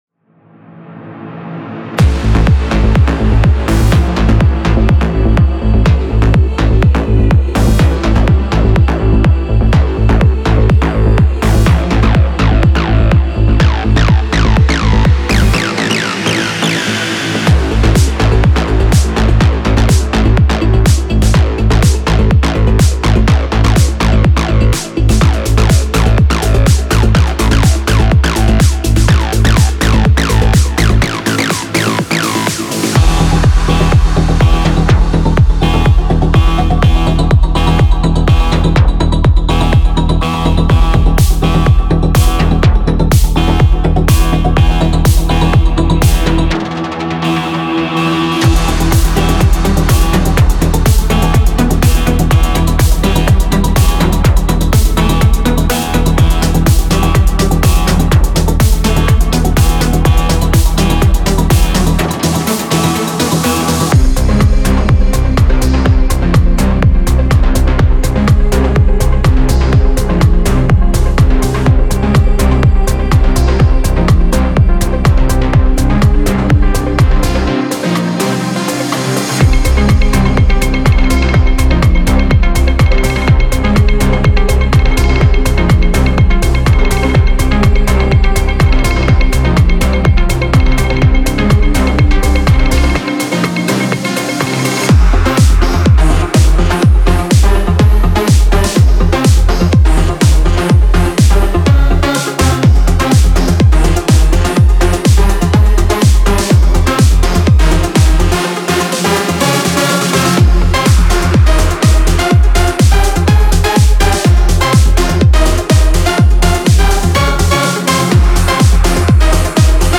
Genre:Melodic Techno
メロディックテクノ、プログレッシブハウス、関連ジャンルに最適で、ループはすべて安定した124BPMで用意されています。
ドラムはグルーヴィーなシンセベースと組み合わされており、さらにグルーヴ感を増した魅力的な仕上がりとなっています。
デモサウンドはコチラ↓